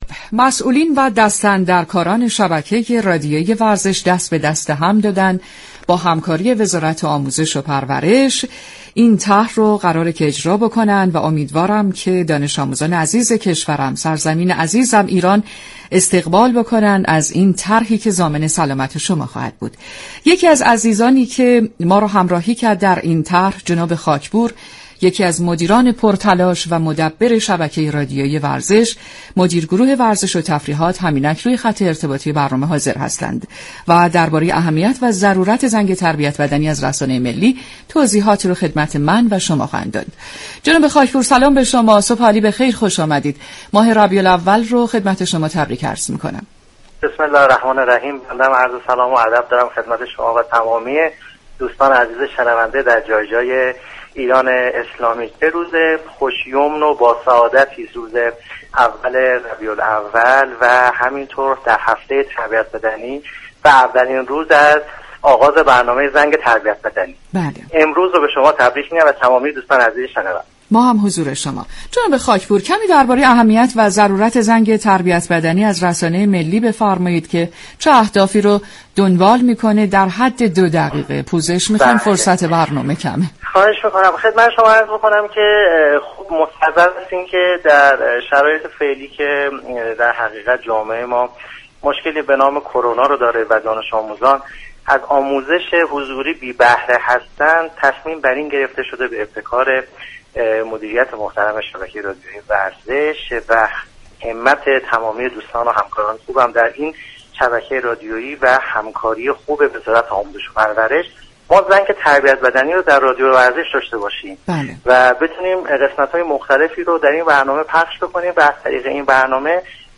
در گفتگو با برنامه «زنگ تربیت بدنی»